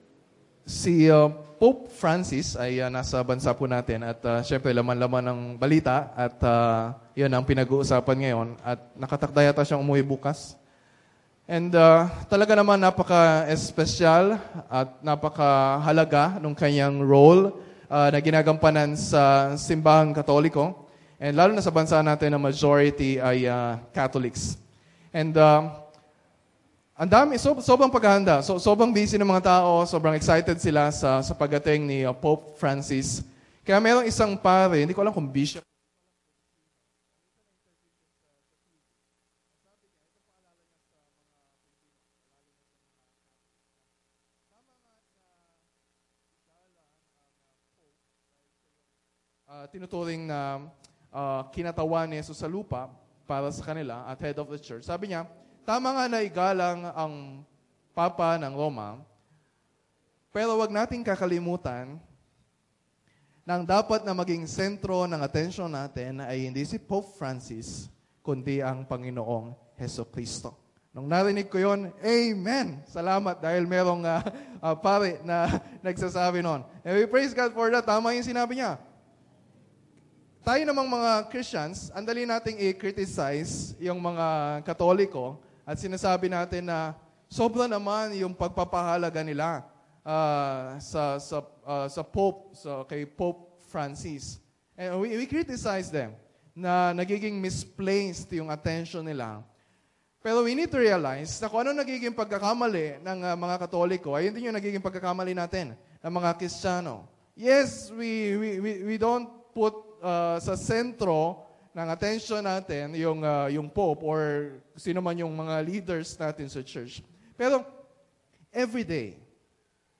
Life is all about Jesus. Paulit-ulit na natin iyang napag-uusapan sa sermon series natin sa Luke.